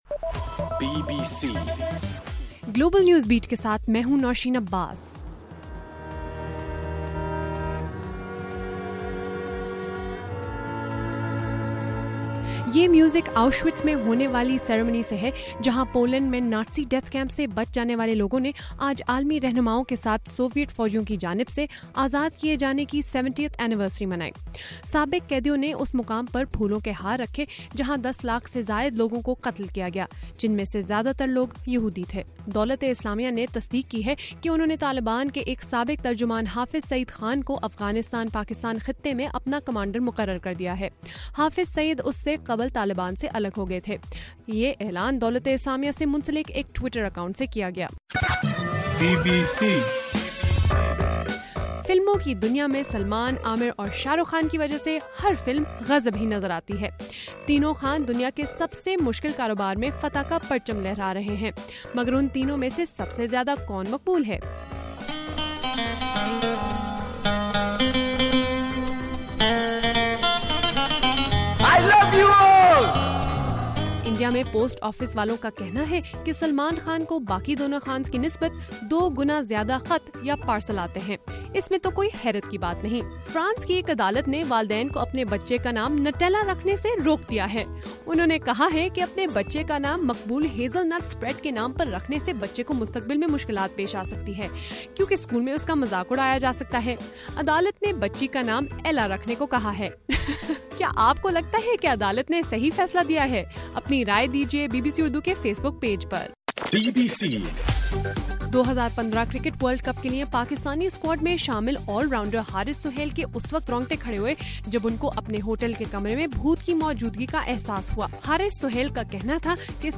جنوری 27: رات 11 بجے کا گلوبل نیوز بیٹ بُلیٹن